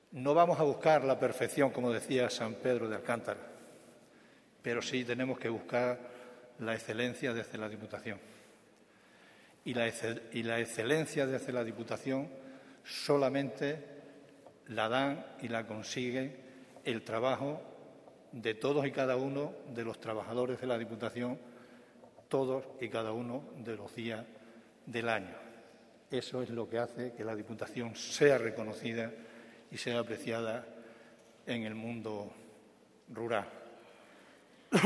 El presidente de la Diputación de Cáceres preside el tradicional acto de homenaje a los jubilados y jubiladas, coincidiendo con la festividad del patrón de la institución, San Pedro de Alcántara. 19/10/2021, Cáceres.- Sin duda, ha sido un acto muy especial.